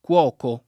cuoco [
kU0ko] s. m.; pl. -chi — pop. tosc. coco [